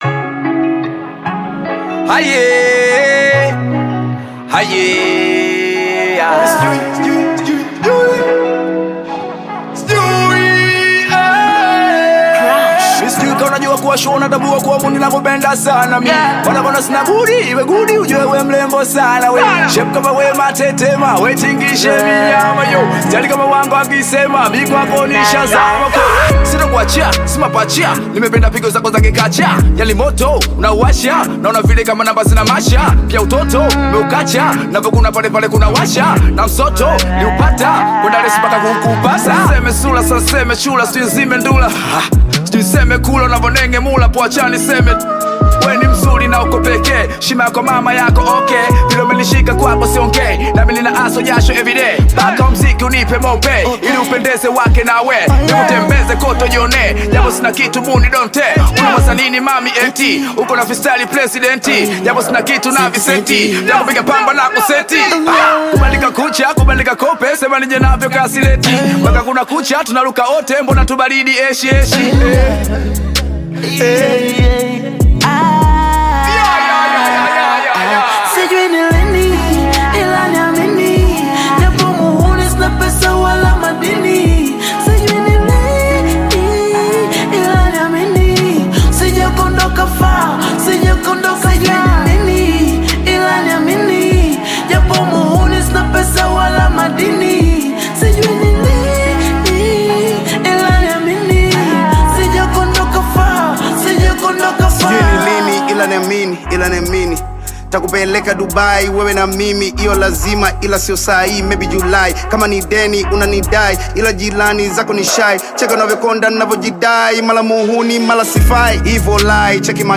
Tanzanian Bongo Flava
is an enthralling hip-hop trap song